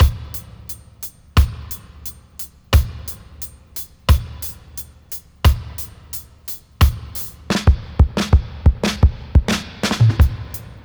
88-FX-09.wav